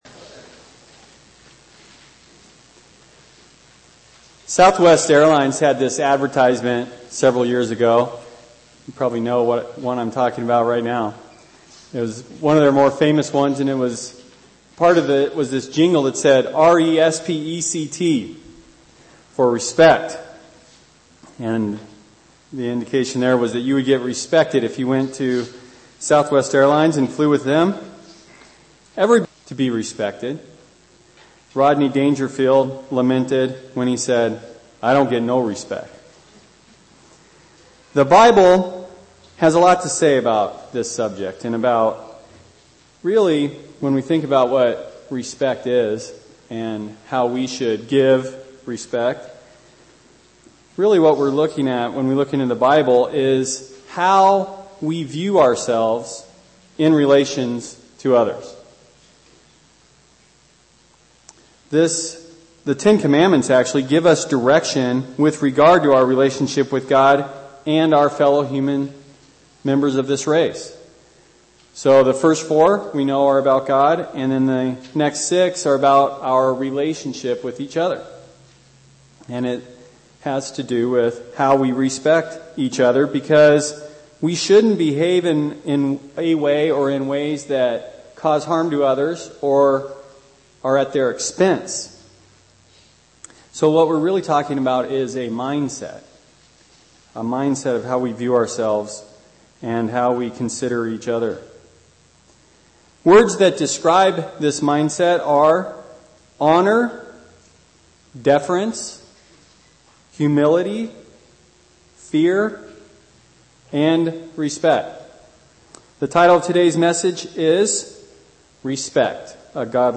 Sermons
Given in Phoenix East, AZ